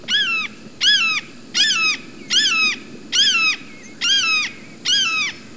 The Red Shouldered Hawk
Click on the picture above to hear the red-shouldered hawk.